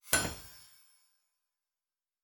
Blacksmith 04.wav